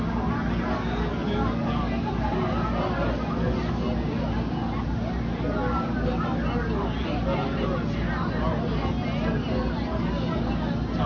• Interactive reading → crowd
[sound of a crowd]